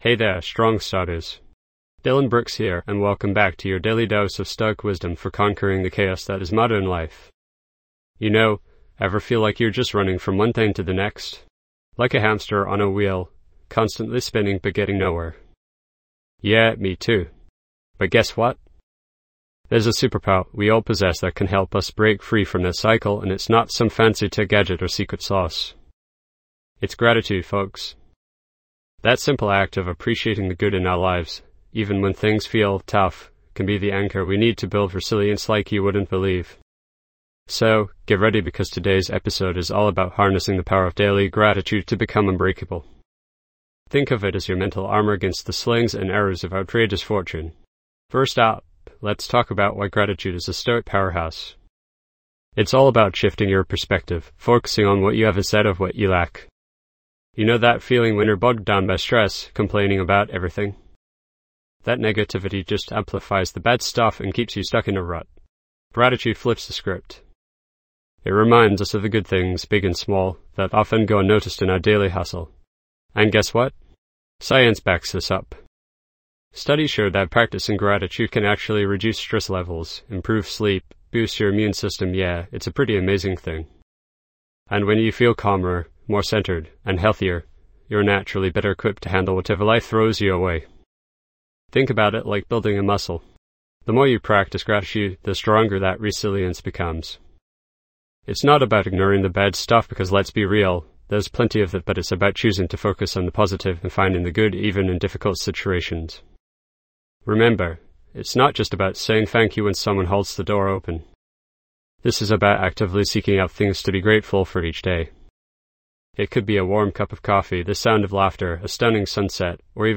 Podcast Category:. Self-improvement Personal Development, Health Wellness, Mental Health, Mindfulness Meditation, Inspirational Talks
This podcast is created with the help of advanced AI to deliver thoughtful affirmations and positive messages just for you.